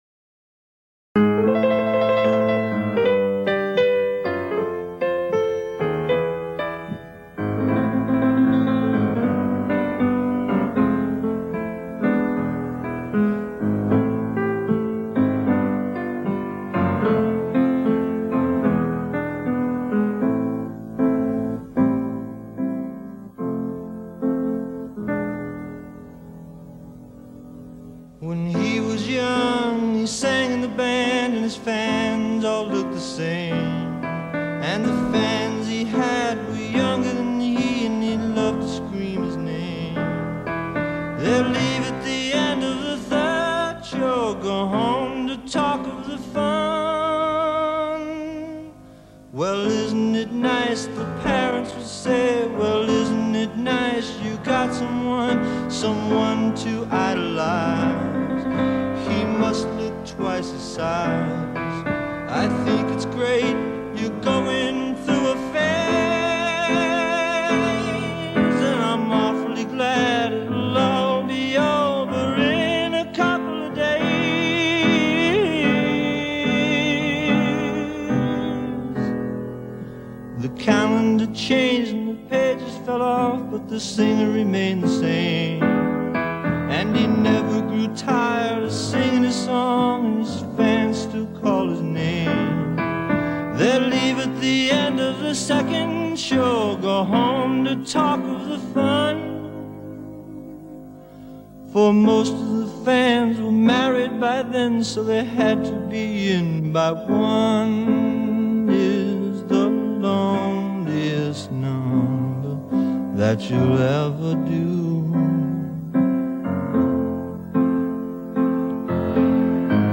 piano
Simple songs for complicated people. https